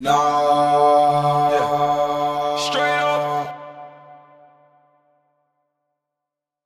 TS Vox_5.wav